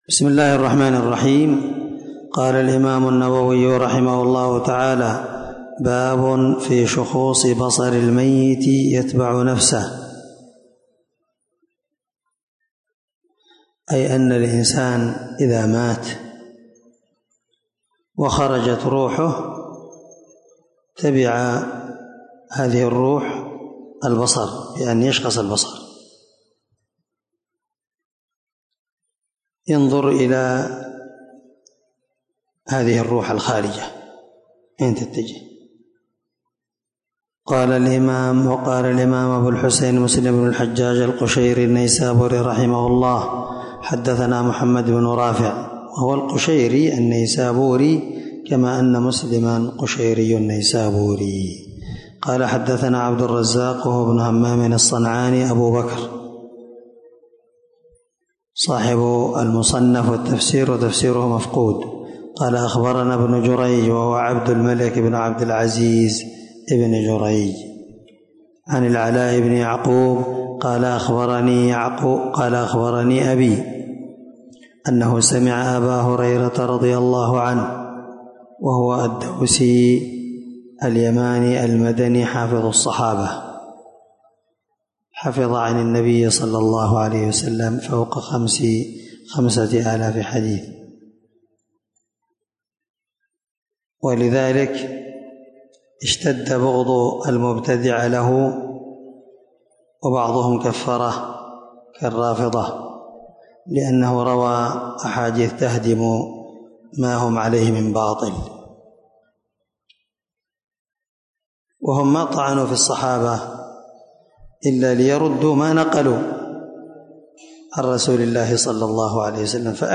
560الدرس 5 من شرح كتاب الجنائز حديث رقم( 921_922 ) من صحيح مسلم